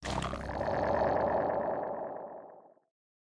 Minecraft Version Minecraft Version latest Latest Release | Latest Snapshot latest / assets / minecraft / sounds / block / conduit / attack1.ogg Compare With Compare With Latest Release | Latest Snapshot